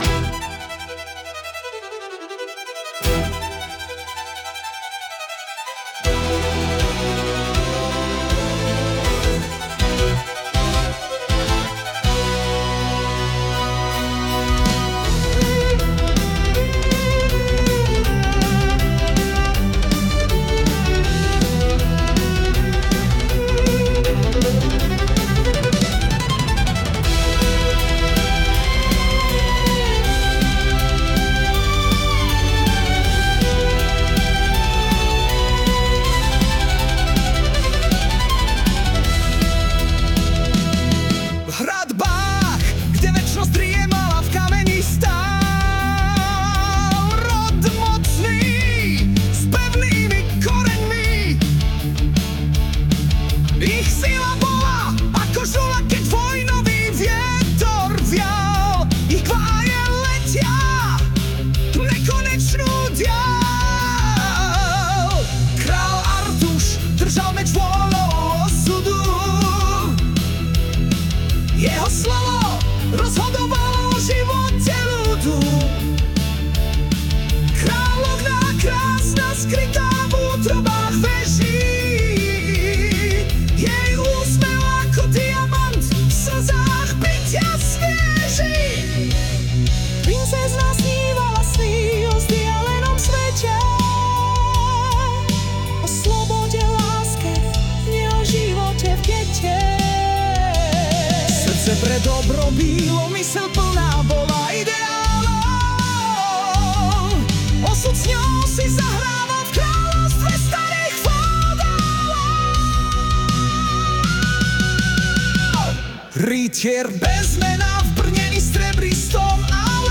Hudba a spev AI
Balady, romance » Ostatní
zase ty smyčce - to je síla báje :)*